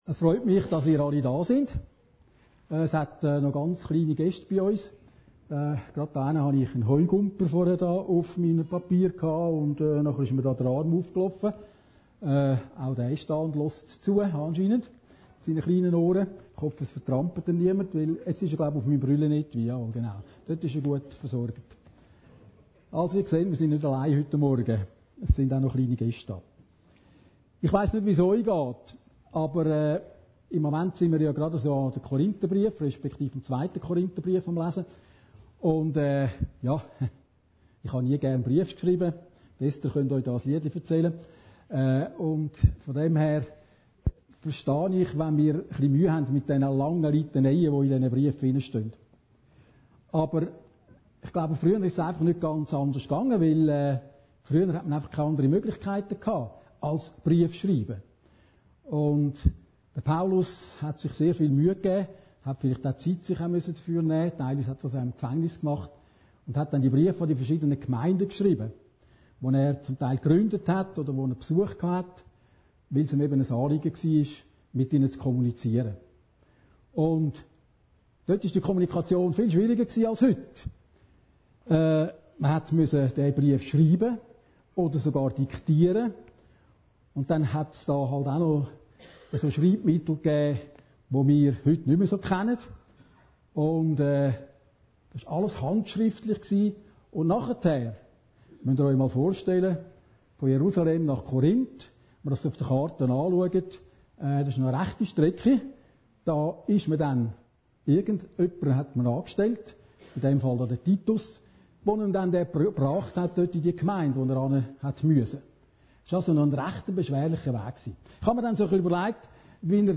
Predigten Heilsarmee Aargau Süd – Investition aus Liebe